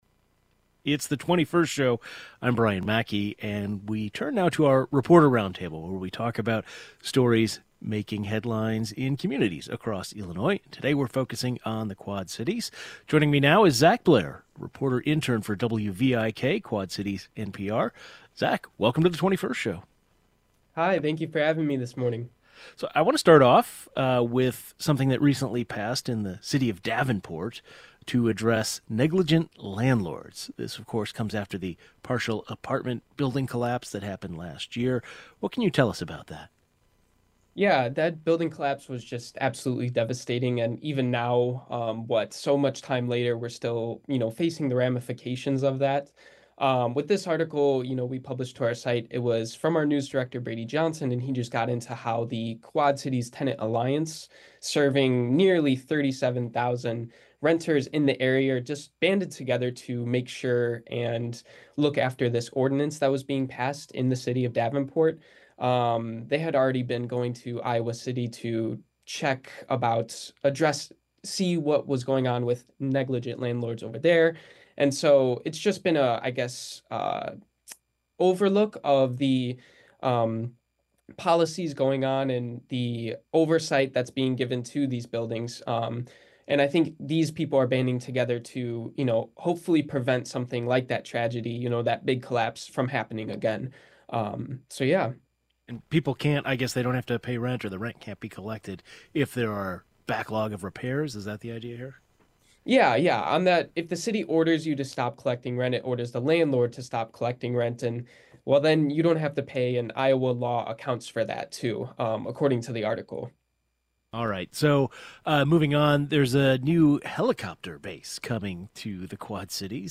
This week, our reporter roundtable is focusing on the Quad Cities, including a new rent abatement following last year's partial apartment collapse and a new helicopter base for Quad City hospitals.